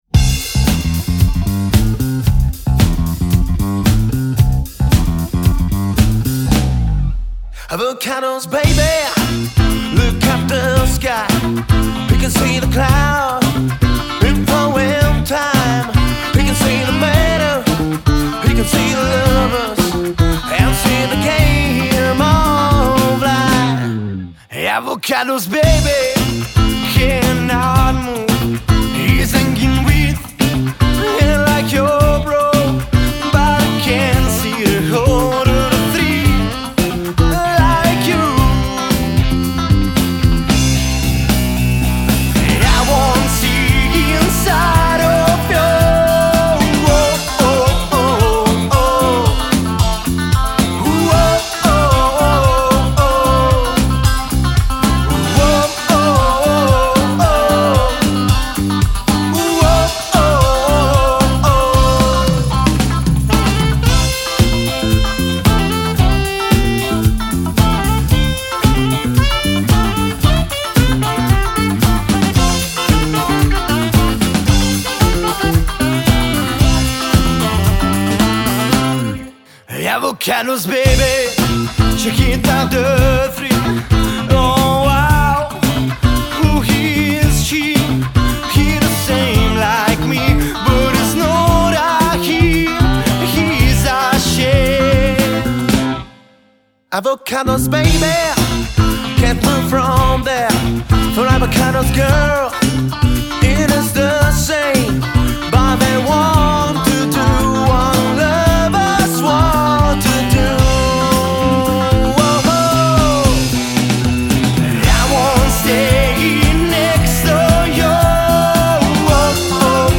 Genere: Pop/Rock.